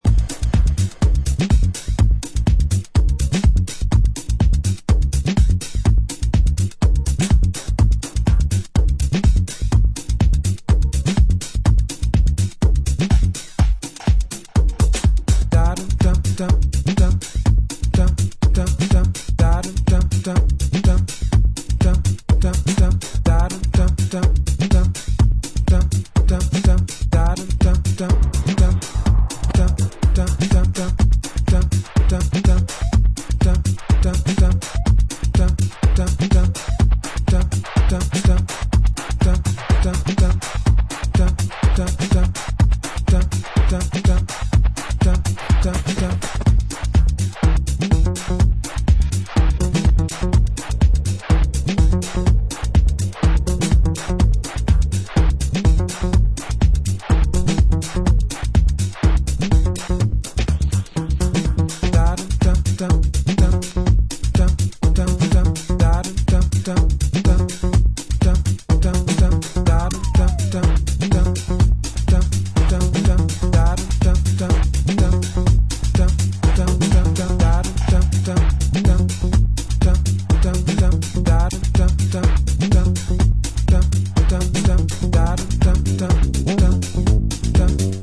techno
4 tracks classically Chicago-influenced sound to the fore!